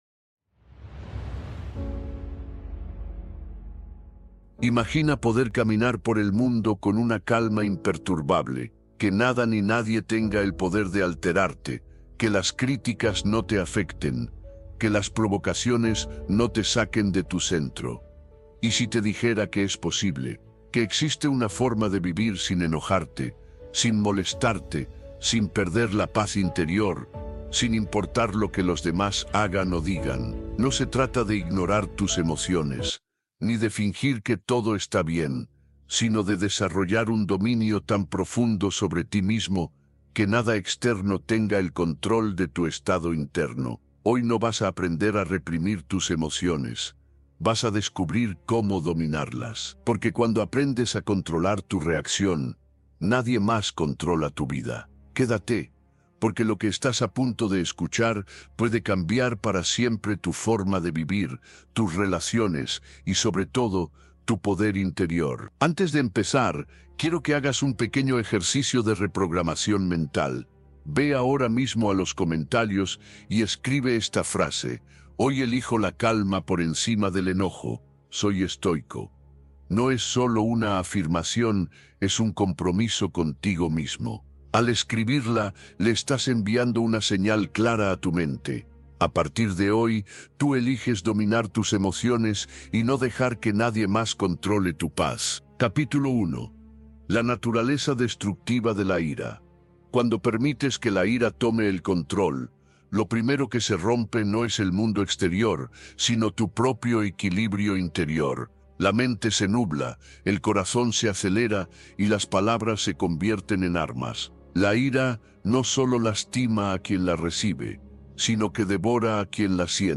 ¡Nunca Más Te Enfurescas! Descubre Cómo Mantener la Calma con Cualquier Persona | AUDIOLIBRO